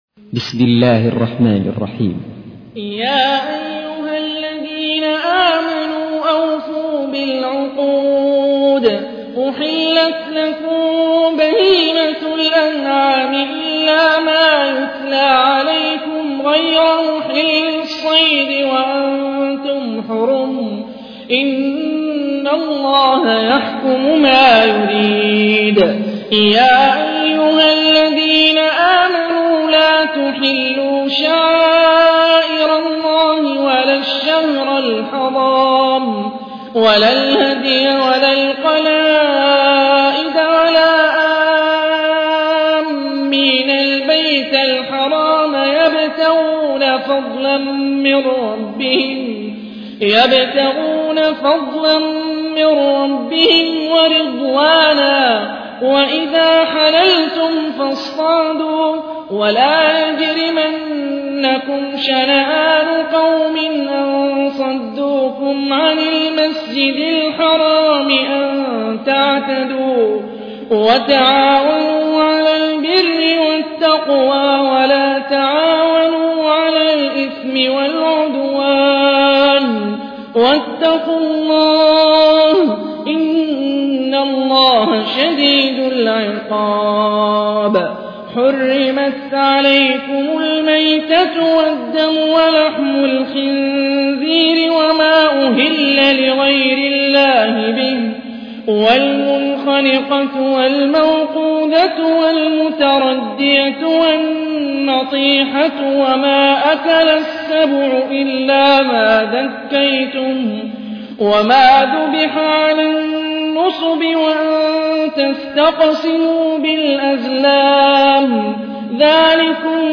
تحميل : 5. سورة المائدة / القارئ هاني الرفاعي / القرآن الكريم / موقع يا حسين